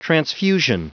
Prononciation du mot transfusion en anglais (fichier audio)
Prononciation du mot : transfusion